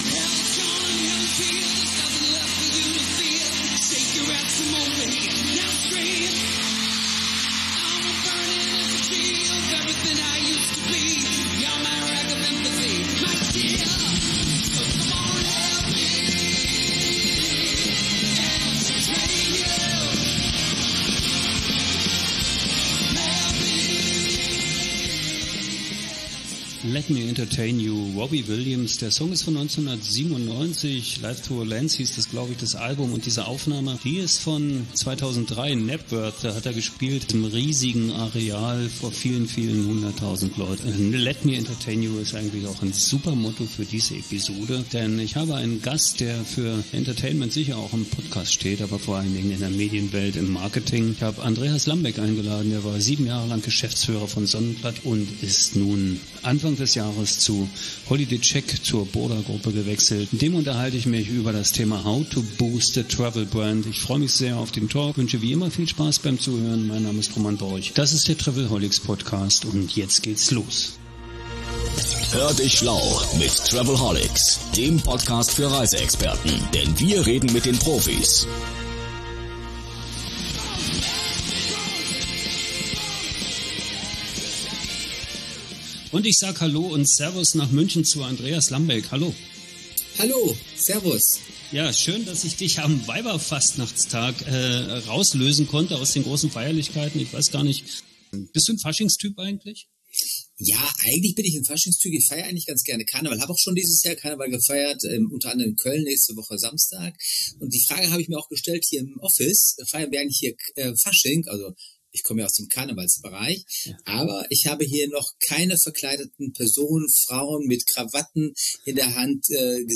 Viel Emotion ist im Spiel, wenn es im Talk mit dem Gast in dieser Episode des Podcast der Touristik um kreative Ansätze für Marketing und Kommunikation in der Reisewirtschaft geht.